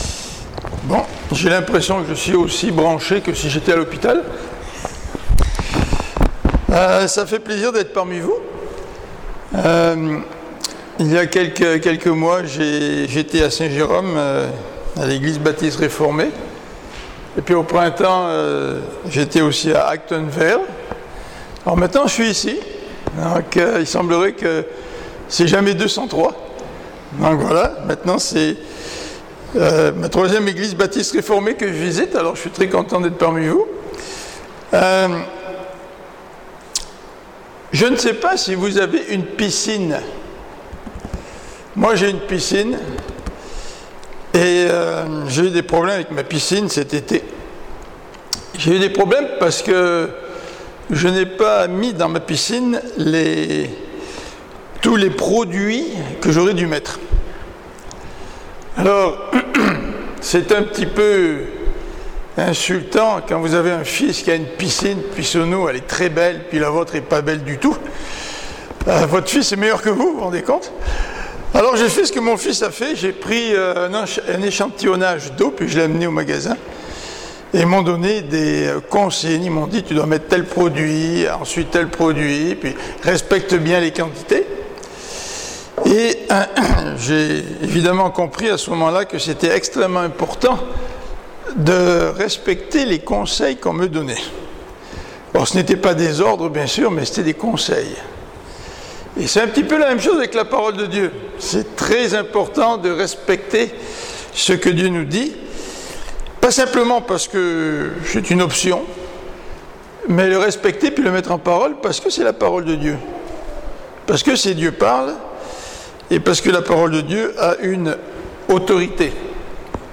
Prédications – Page 12 – Église De La Trinité